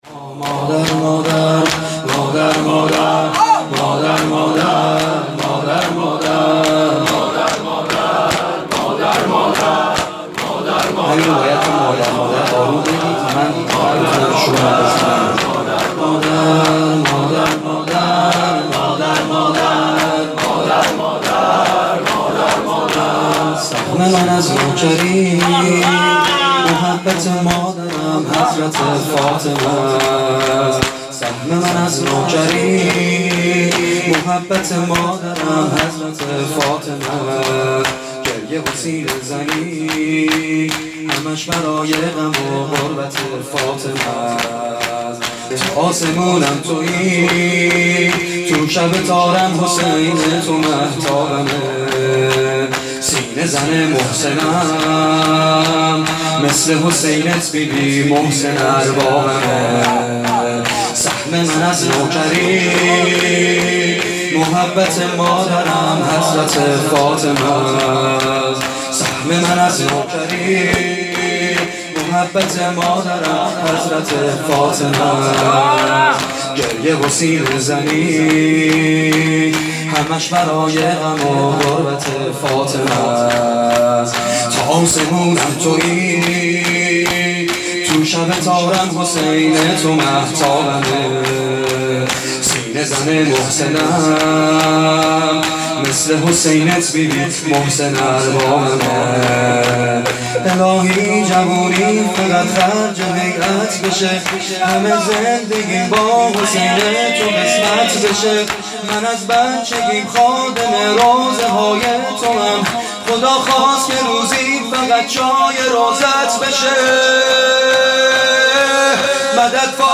فاطمیه